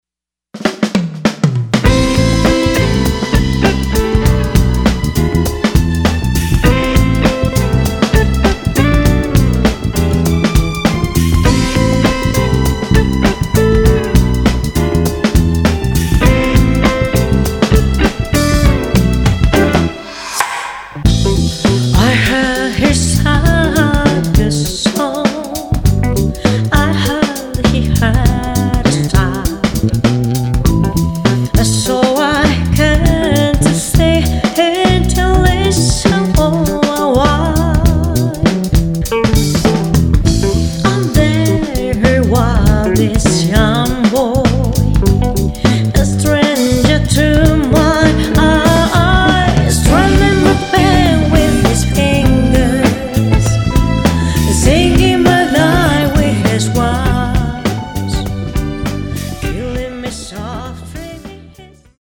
ジャズの枠を超え、ラテン、ファンク、ロック、レゲエなど
あらゆるビートのエッセンスを取り込んだアンサンブル。
Vocal
Guitar
Bass
Piano